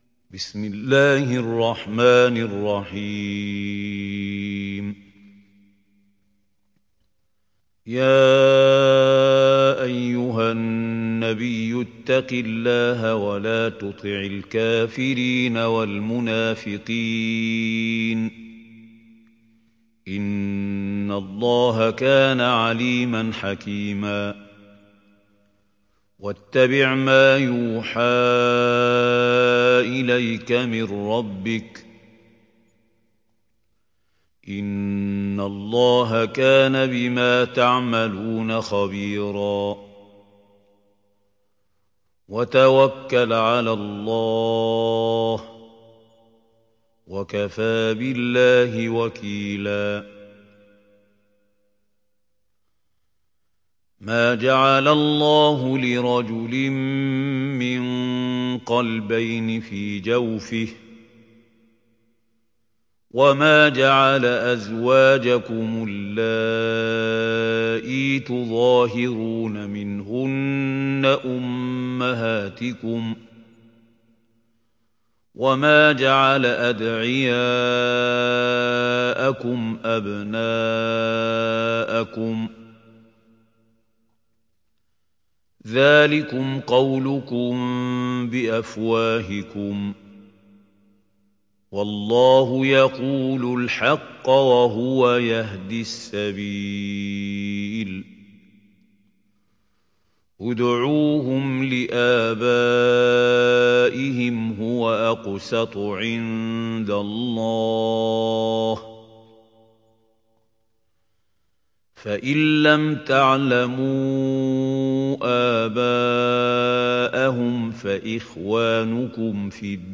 Surat Al Ahzab Download mp3 Mahmoud Khalil Al Hussary Riwayat Hafs dari Asim, Download Quran dan mendengarkan mp3 tautan langsung penuh